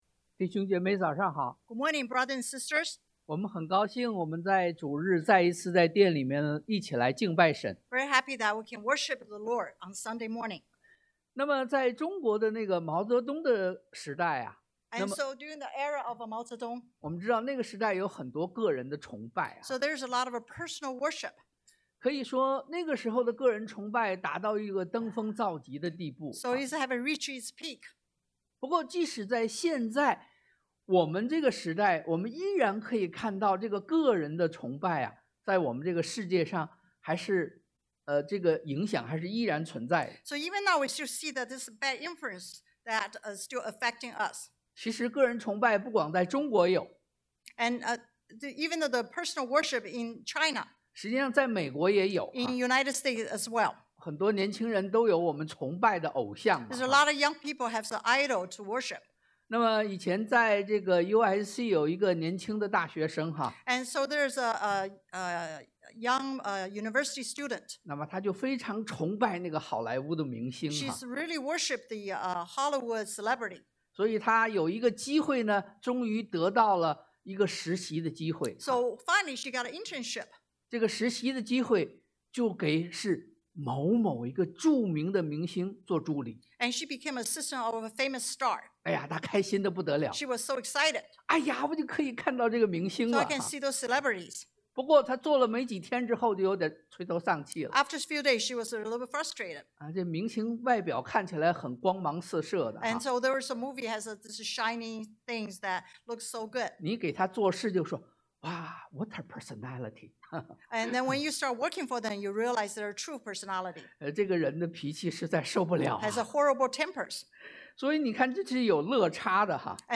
但 Dan 3:1-18 Service Type: Sunday AM 1.